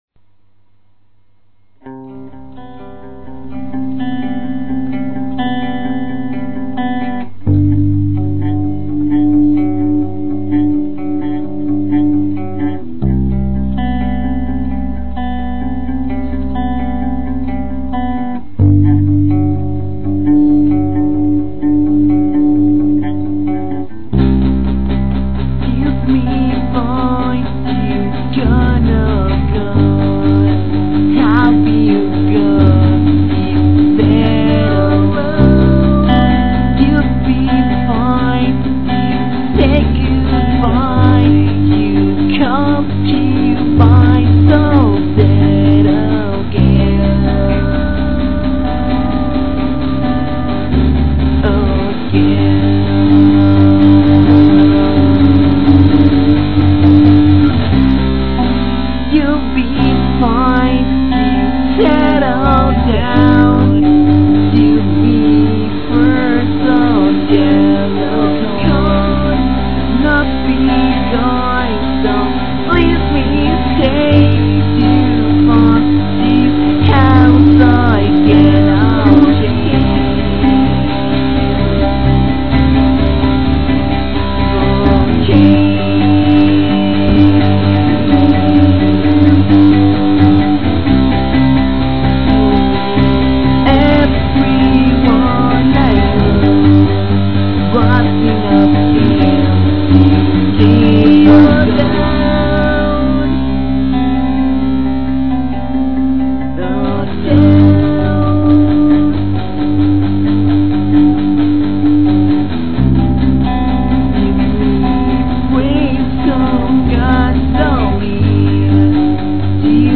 mescla de emo con romanticismo..jajaja.